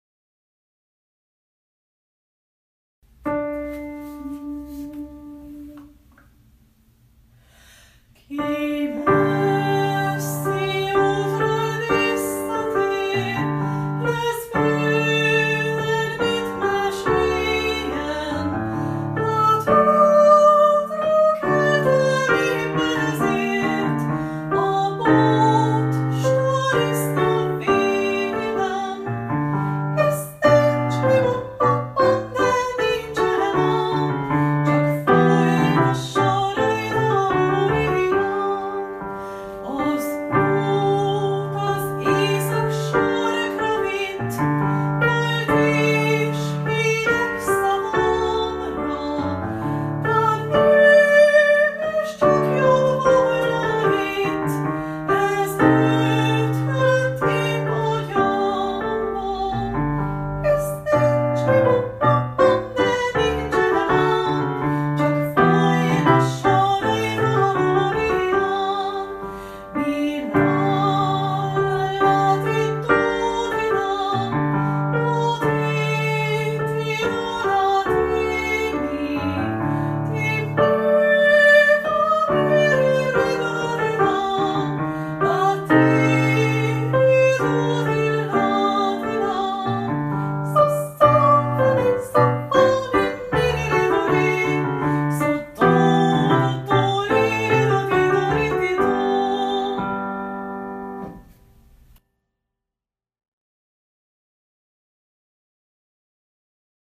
A változatosság kedvéért a felvételek otthon készültek. Vigyázat, nyomokban speciális effekteket (zajokat) tartalmazhatnak.